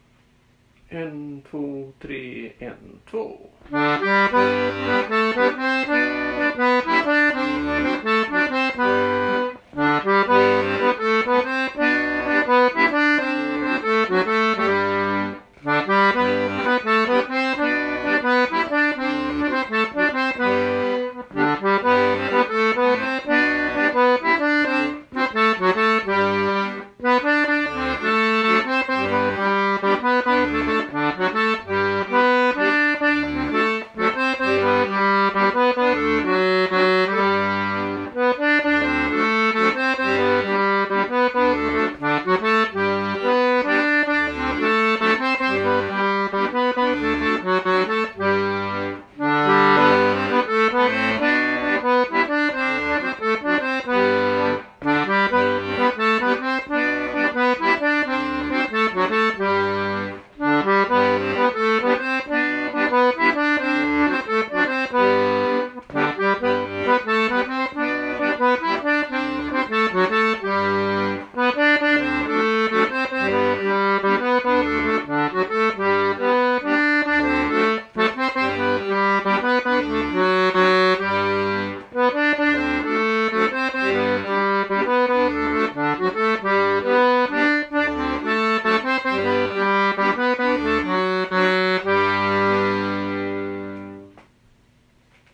Allspelslåtar spelmansstämman 2025
Vals efter Hampus August, Västerbyn, Stjärnsund, i G-dur [NOTER]